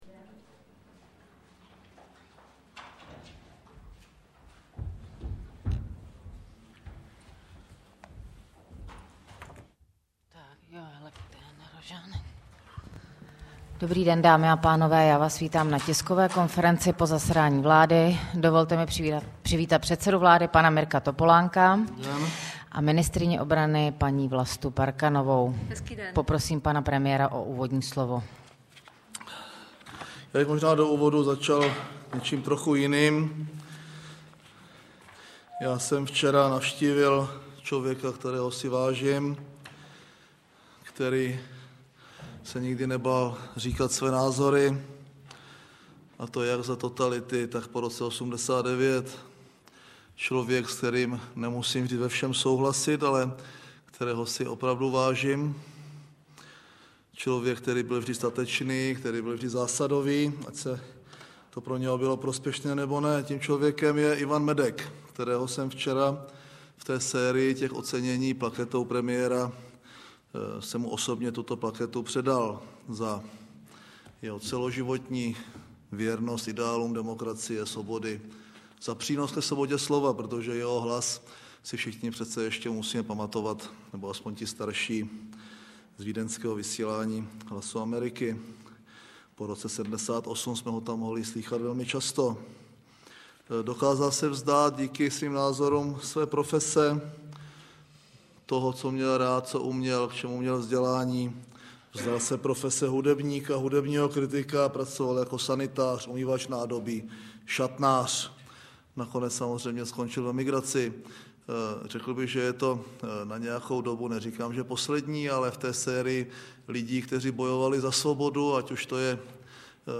Tisková konference po jednání vlády ČR 5.3.2008